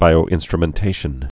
(bīō-ĭnstrə-mĕn-tāshən)